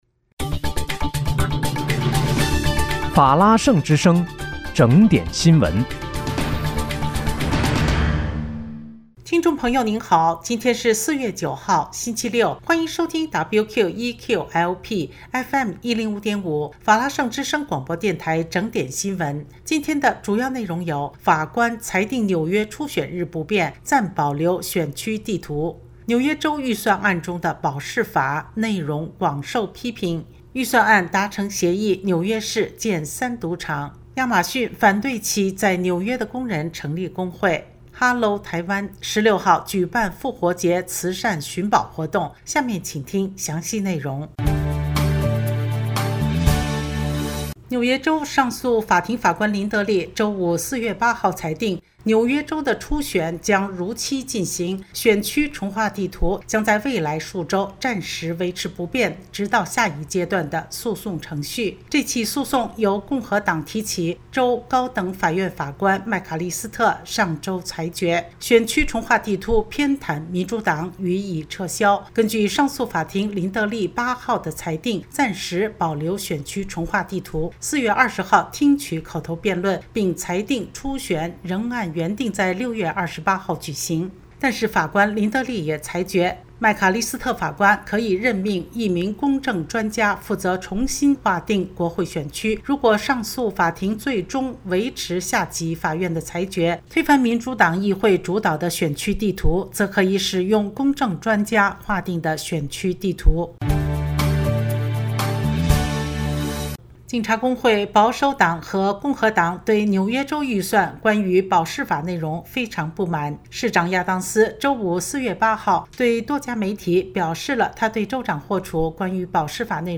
4月9日（星期六）纽约整点新闻